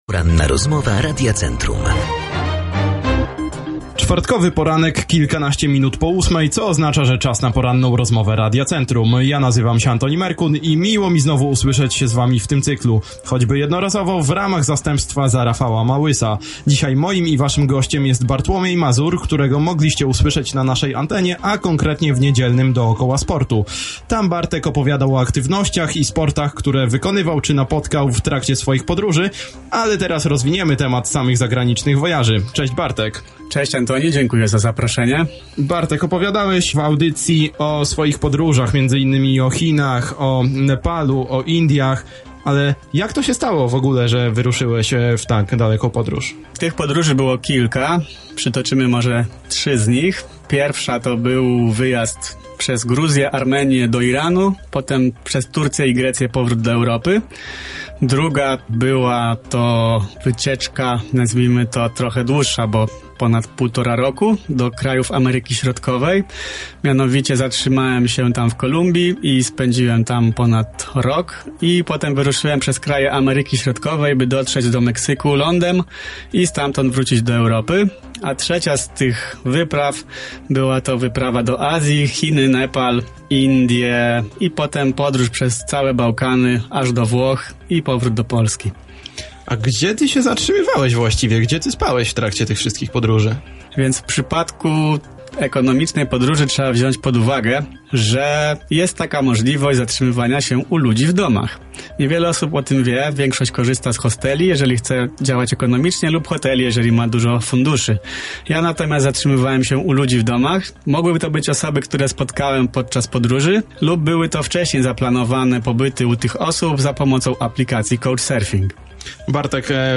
Przemierzył Azję, Europę, Amerykę Południową. Zawędrował też do studia Akademickiego Radia Centrum.